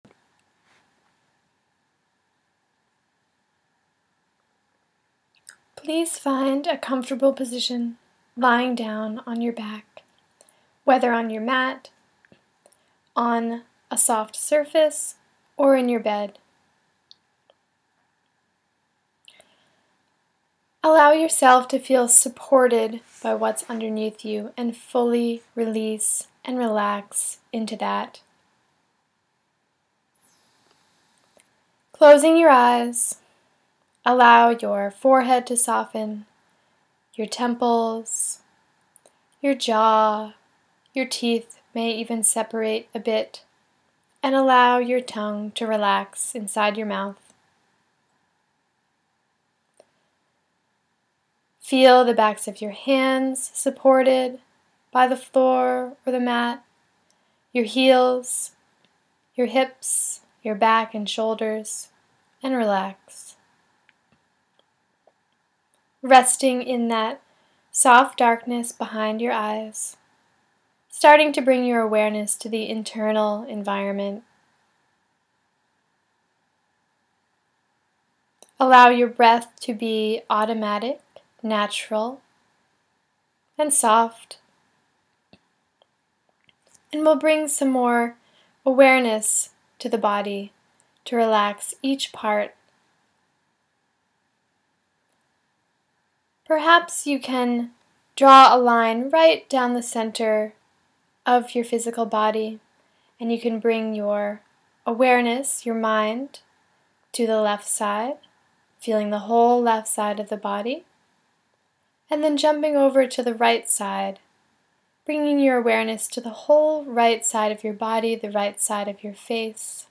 Full Body Relaxation Meditation – Adena Rose Ayurveda